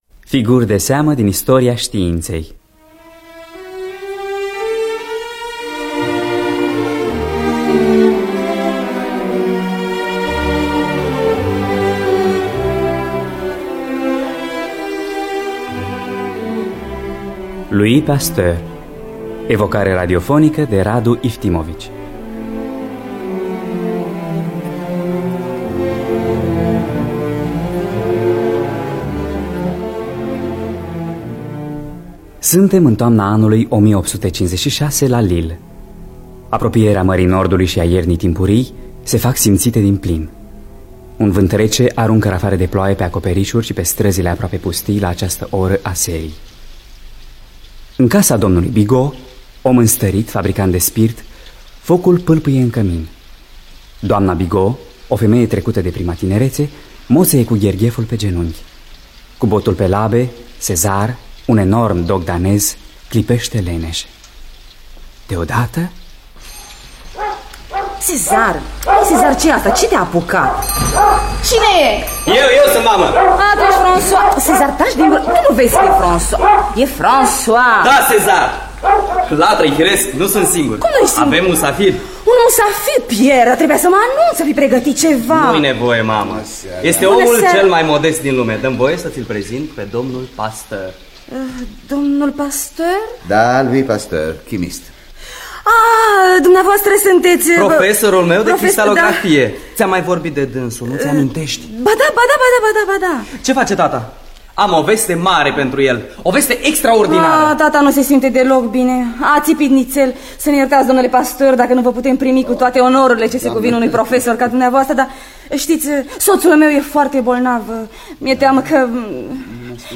Scenariu radiofonic de Radu Iftimovici.